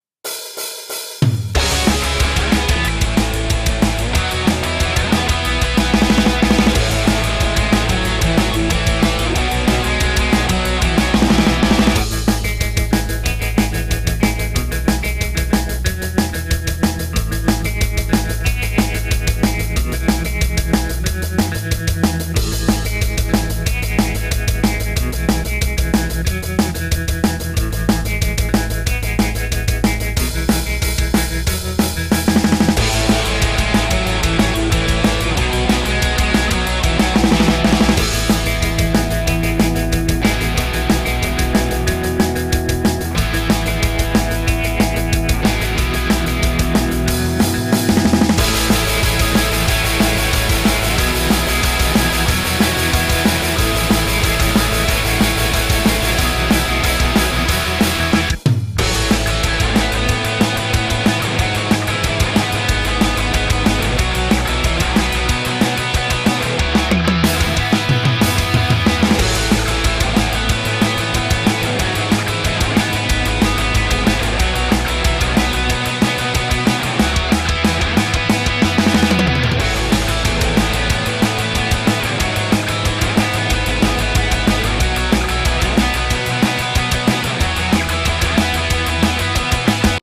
【声劇】君だけの心臓が、